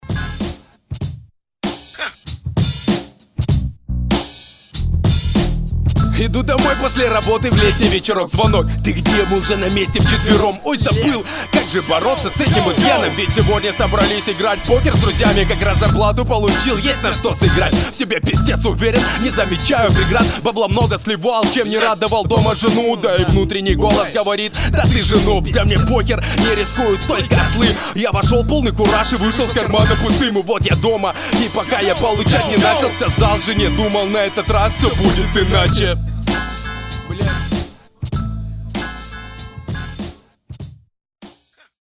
Скучно подаешь, хотя в общем технично.